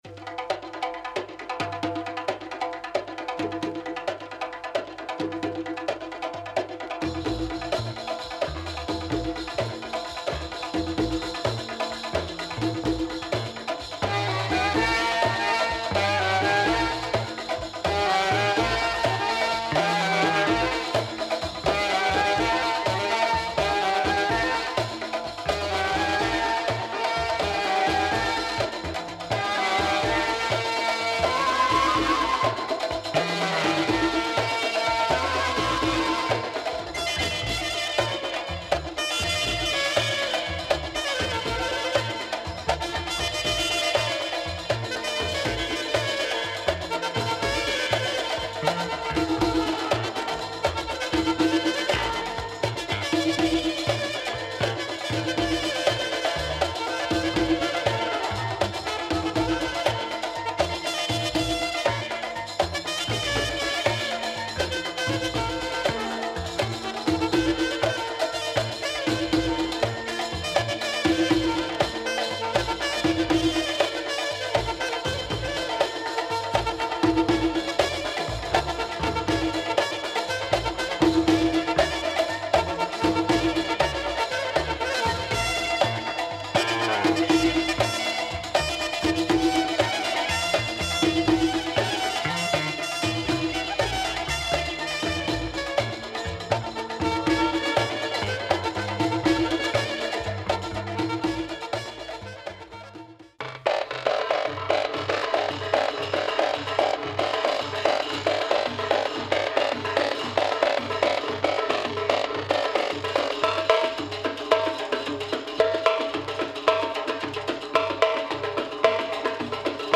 tabla player / percussionist